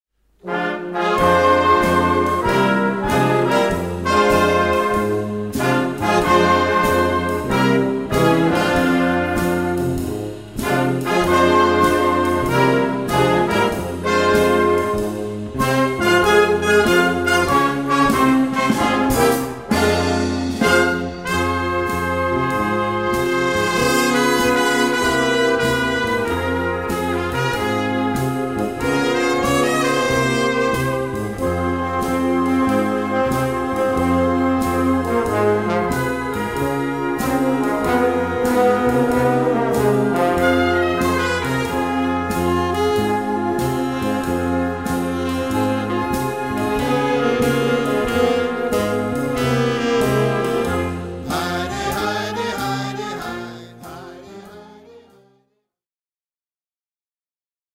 Gattung: 5-Part Flexible + Percussion
Besetzung: Blasorchester